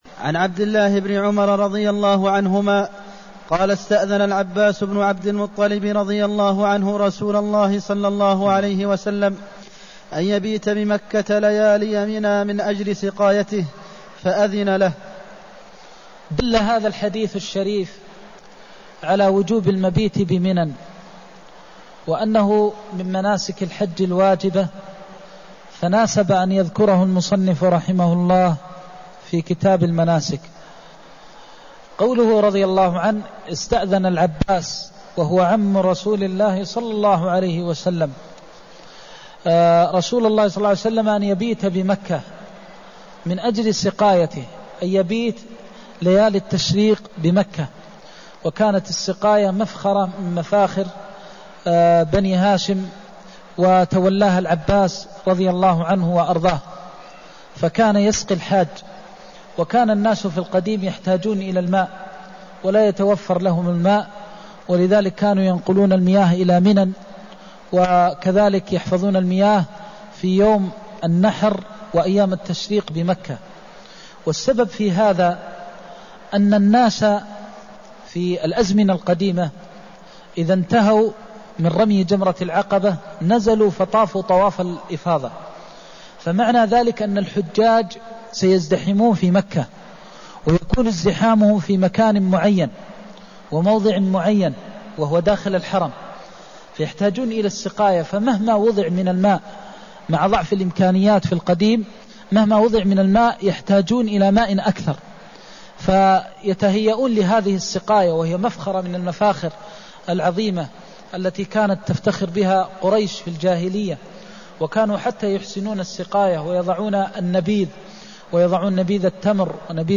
المكان: المسجد النبوي الشيخ: فضيلة الشيخ د. محمد بن محمد المختار فضيلة الشيخ د. محمد بن محمد المختار ترخيص ترك المبيت بمنى لأهل السقاية (239)